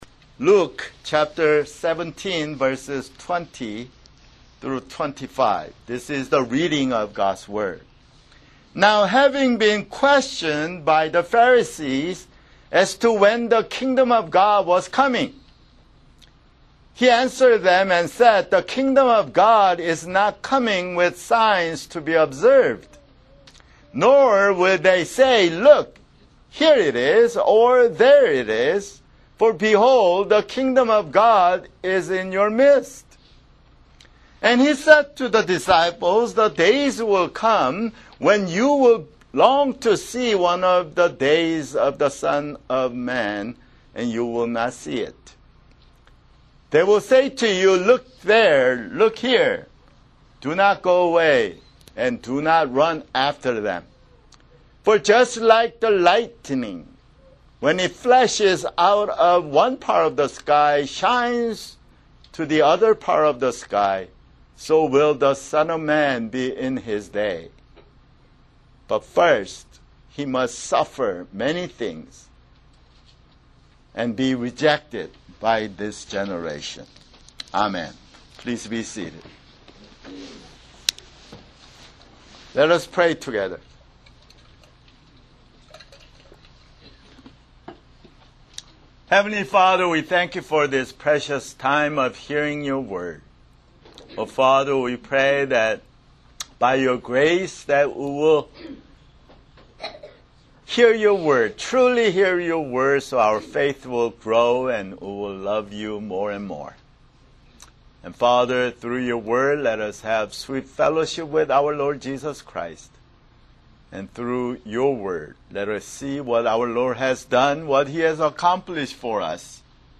[Sermon] Luke (114)